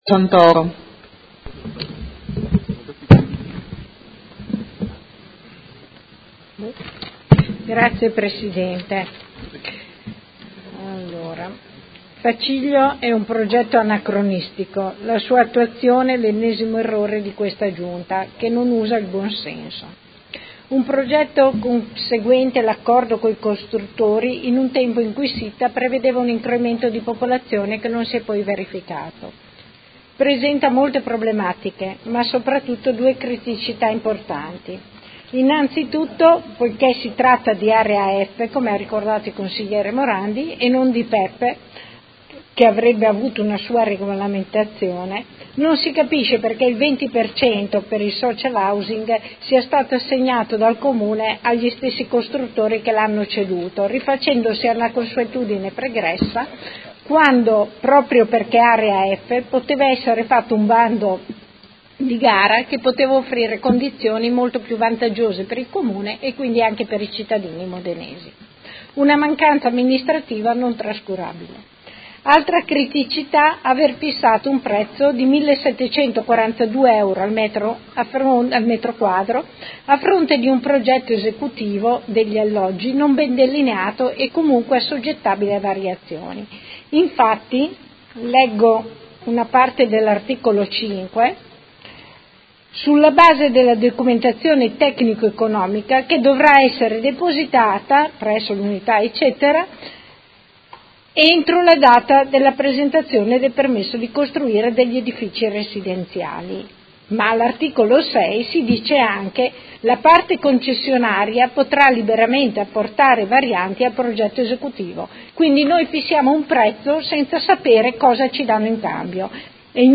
Seduta del 19/07/2018 Dibattito.